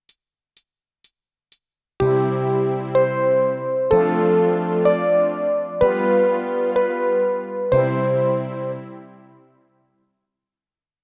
♪和音のつながり(mp3)